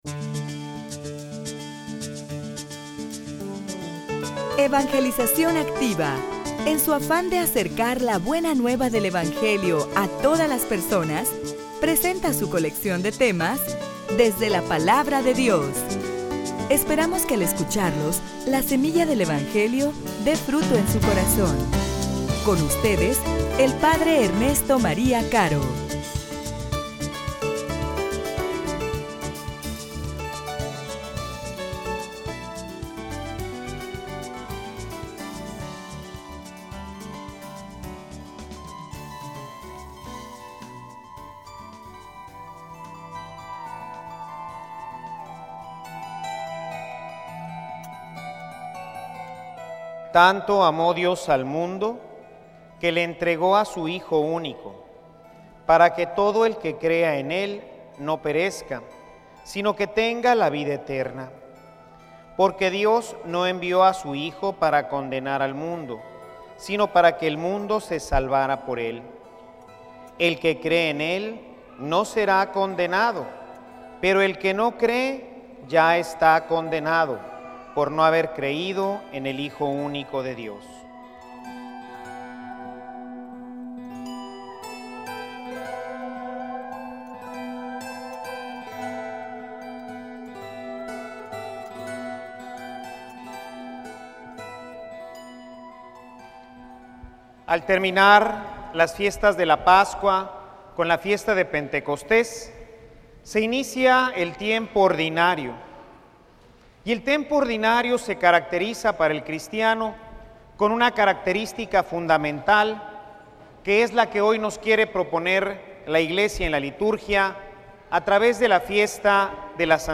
homilia_El_amor_de_los_cristianos.mp3